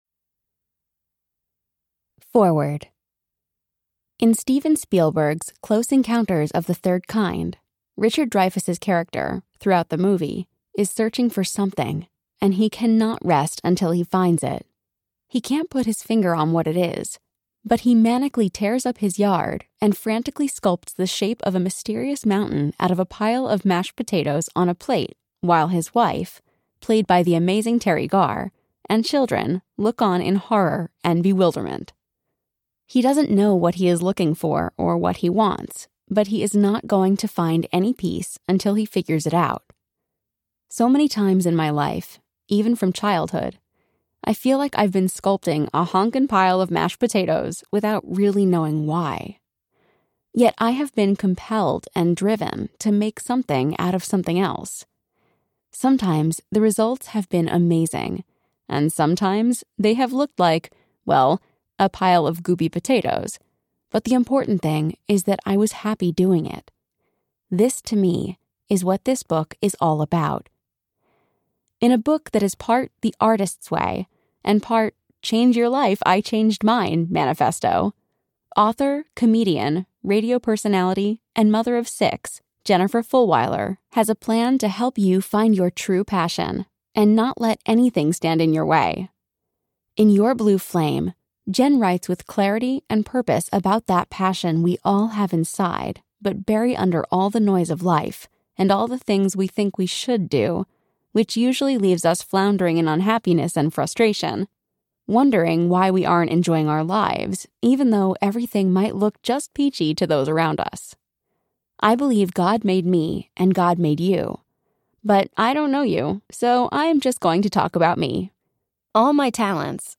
Your Blue Flame Audiobook
5.3 Hrs. – Unabridged